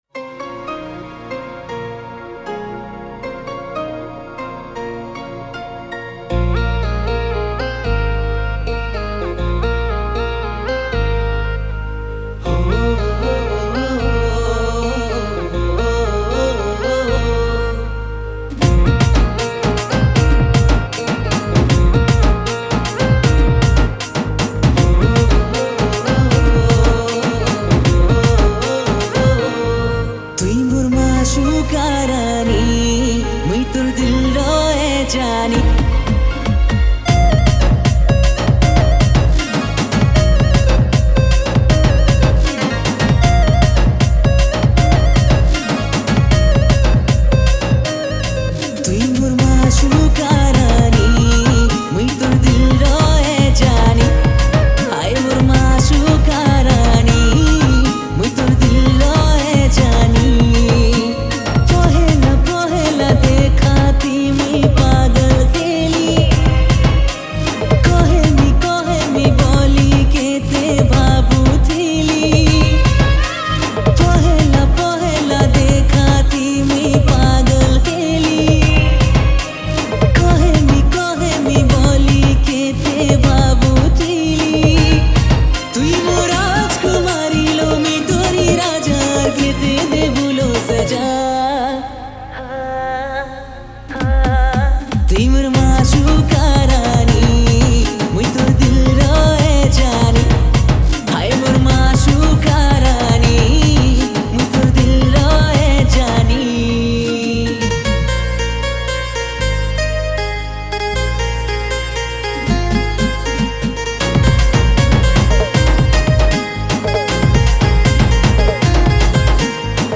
Sambalpuri